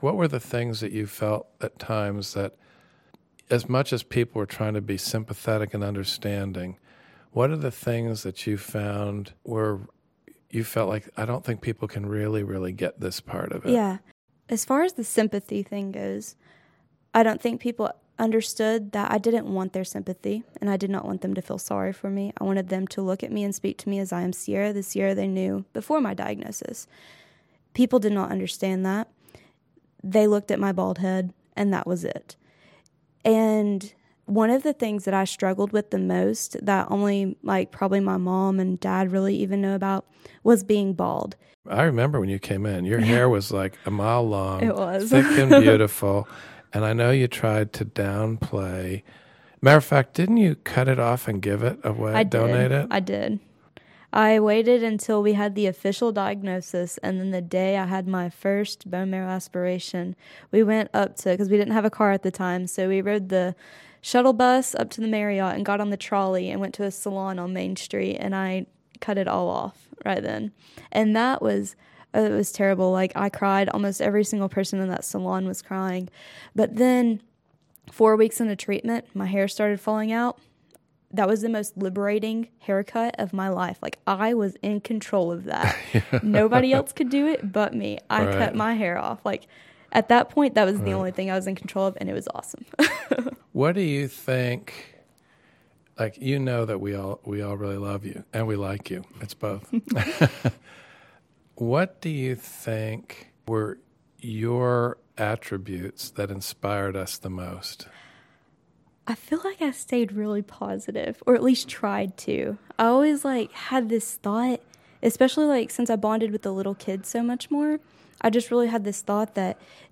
Partnering with the nonprofit oral history project StoryCorps, we asked families, faculty and staff to interview each other and record their shared moments in St. Jude history.